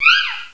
pokeemerald / sound / direct_sound_samples / cries / purrloin.aif
purrloin.aif